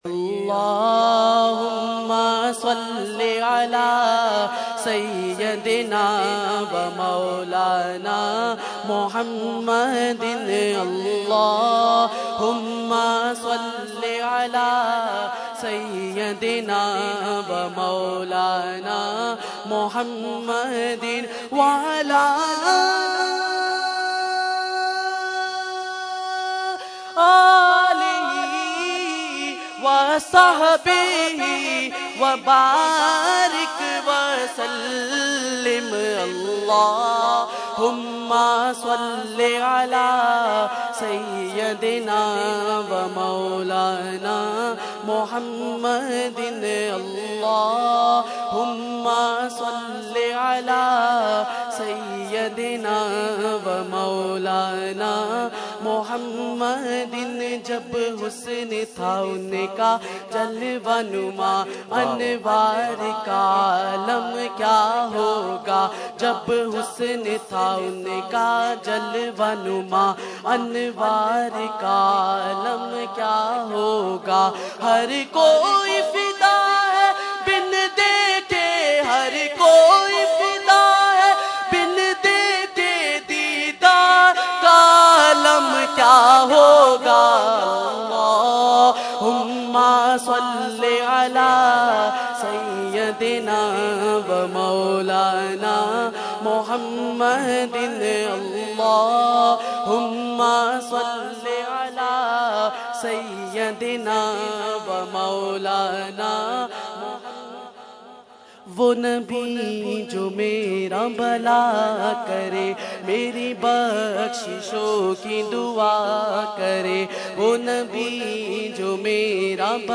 Category : Naat | Language : UrduEvent : Urs Ashraful Mashaikh 2015